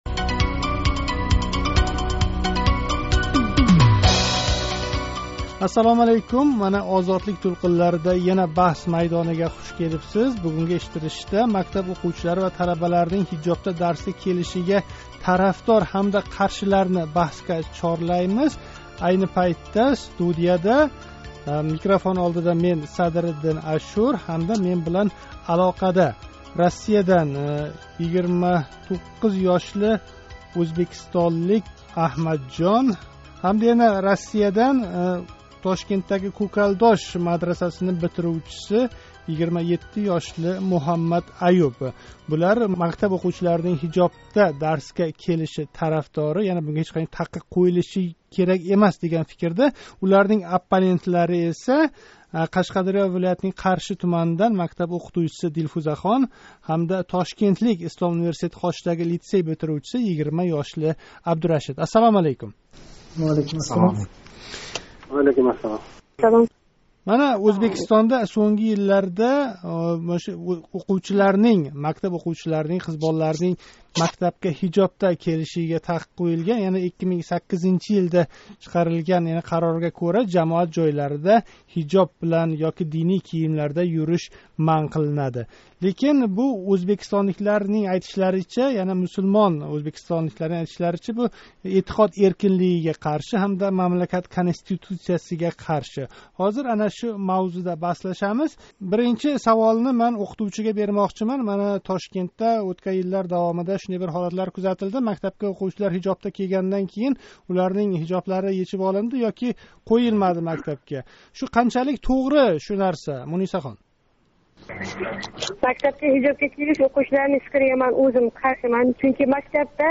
“Баҳс майдони” эшиттиришида мактаб ўқувчилари ва талабаларнинг ҳижобда дарсга келиши тарафдорлари ва унга қарши бўлган тарафлар баҳслашди.